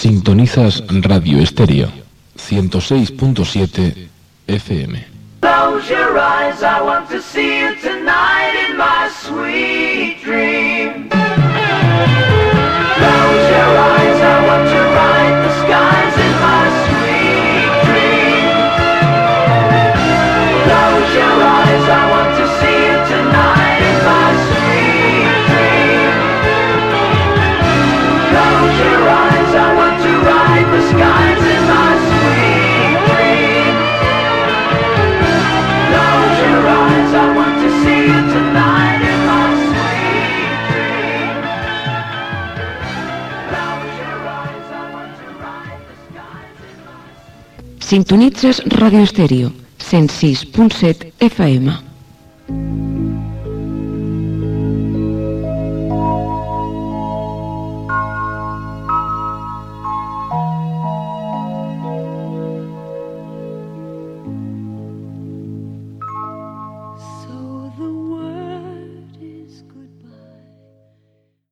Temes musicals
Banda FM